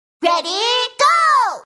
ready-go.mp3